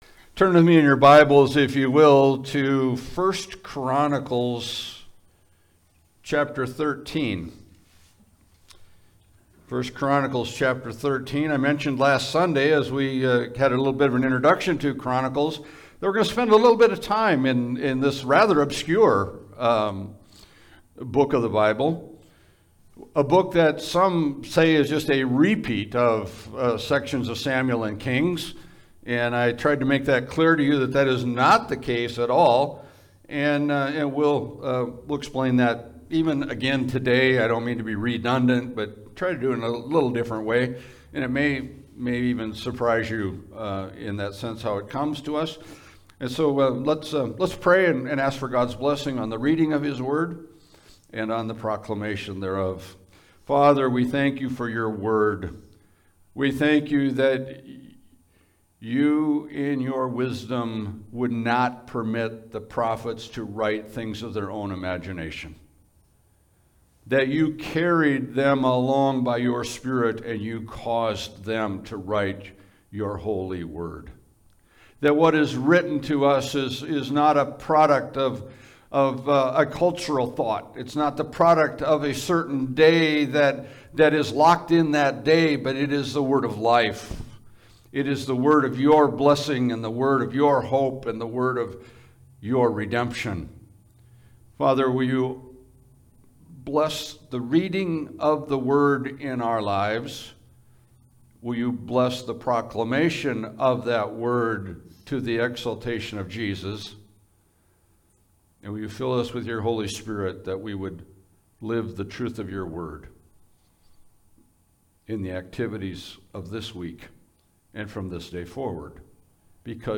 Passage: 1 Chronicles 13 Service Type: Sunday Service